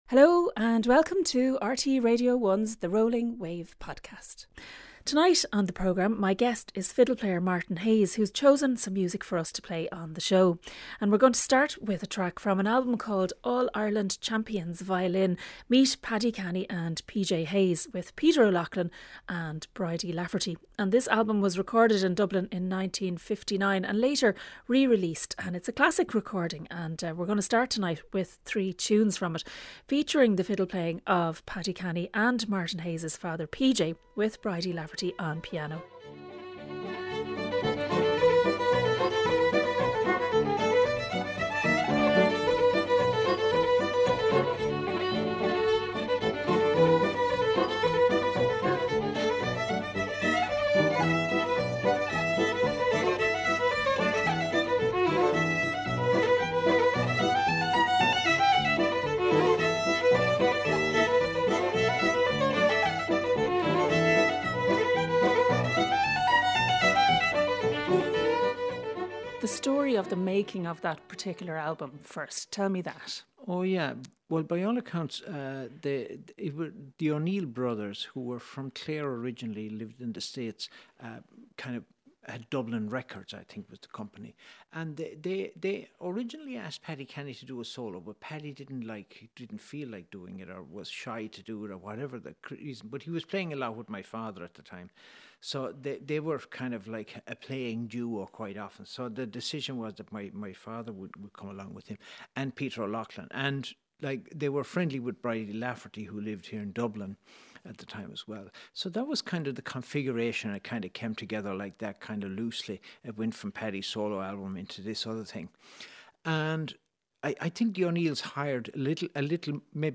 The Rolling Wave celebrates all that is great about Irish Traditional music. We speak to musicians, delve into archives, showcase new releases and visit some of the many traditional music festivals taking place around the country. The Rolling Wave podcast brings you some of our best interviews and features. For rights reasons the music here is shorter than in the original broadcast.